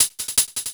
Hats 08.wav